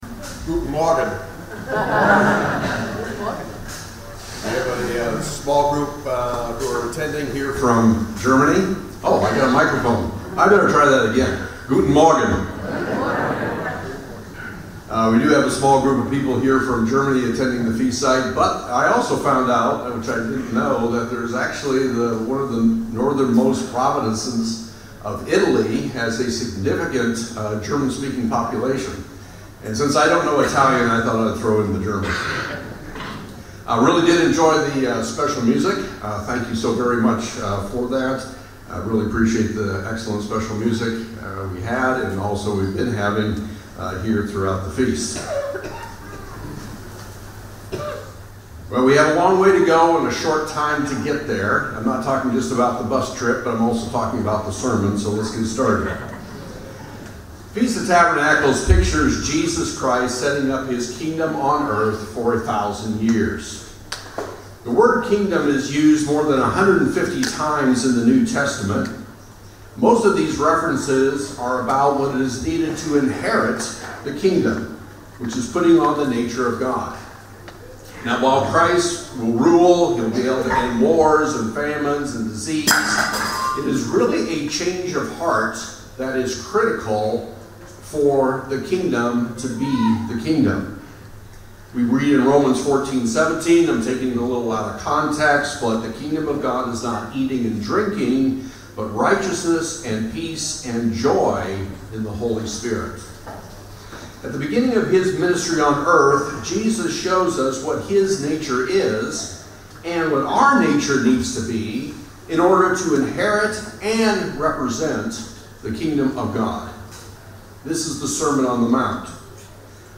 FoT 2024 Marina di Grosseto (Italy): 5th day